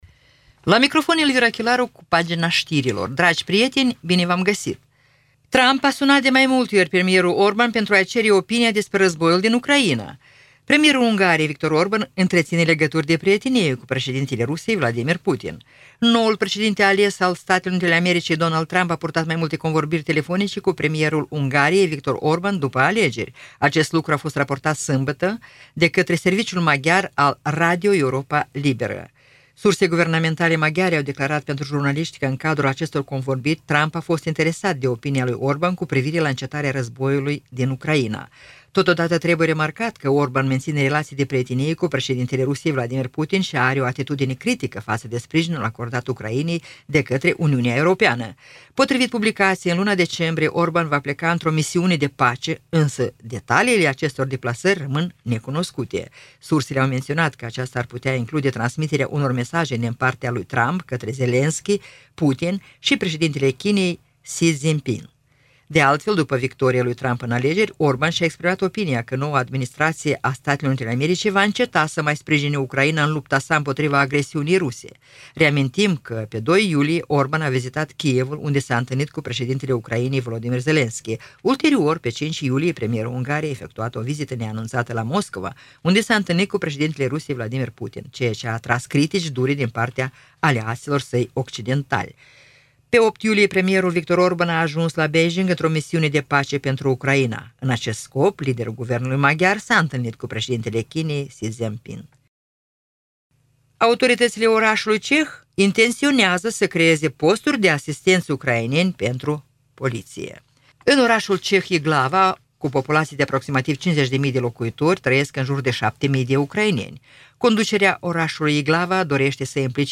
Ştiri Radio Ujgorod – 02.12.2024